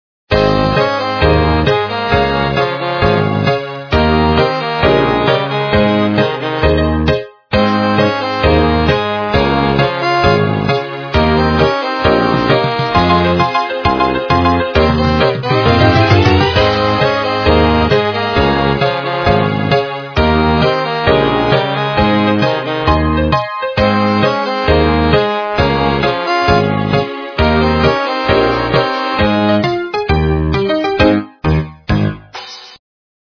- русская эстрада
качество понижено и присутствуют гудки
полифоническую мелодию